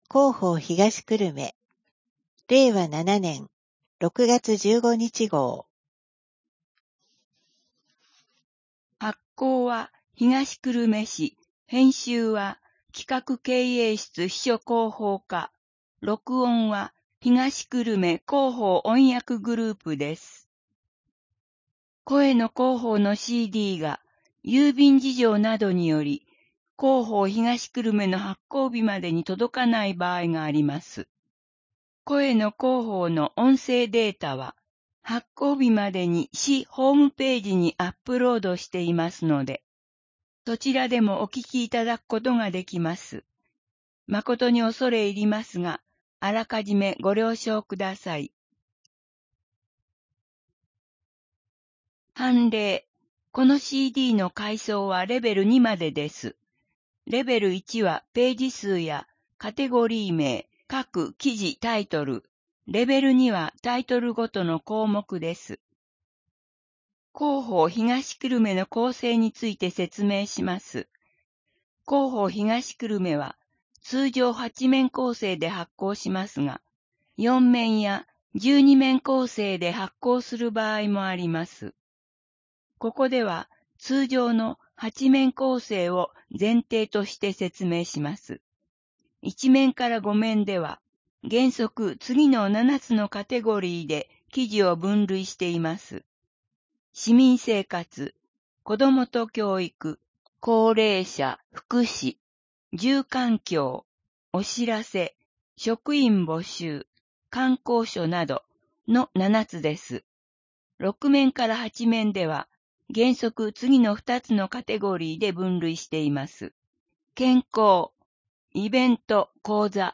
声の広報（令和7年6月15日号）